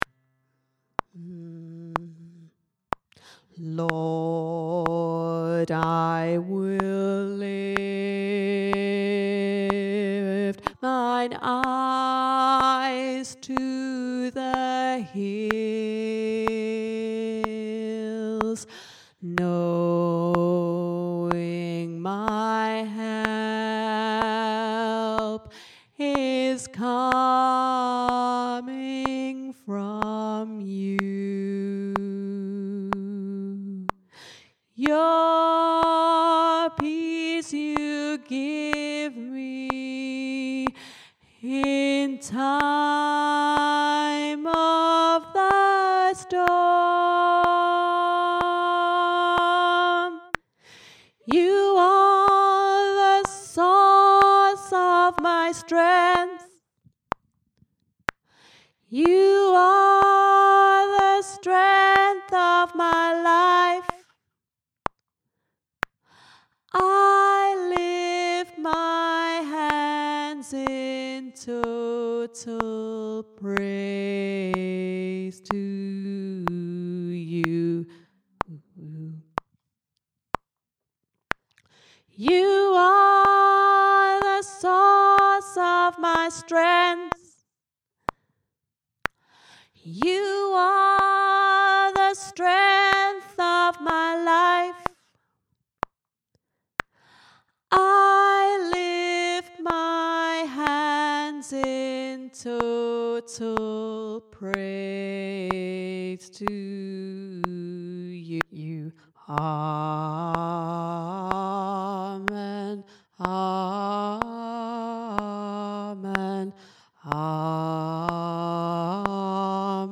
total-praise-tenor2.mp3